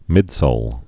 (mĭdsōl)